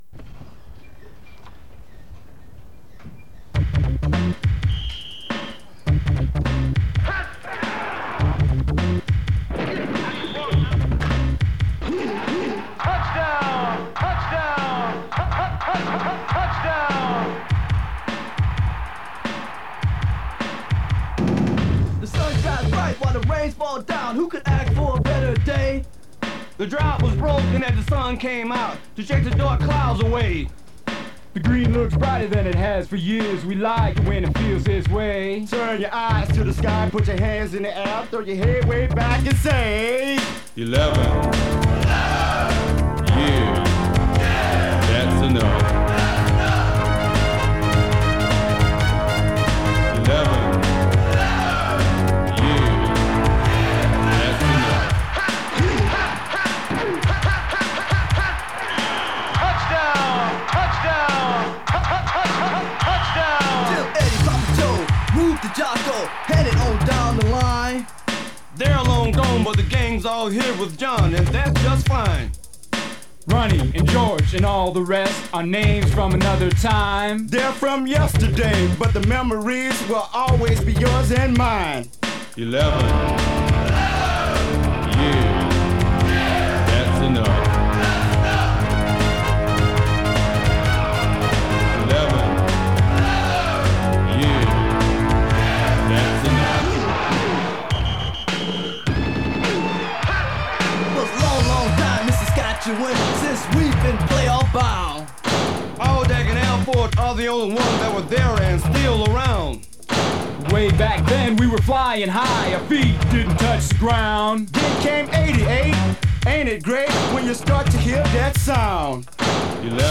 they recorded a rap song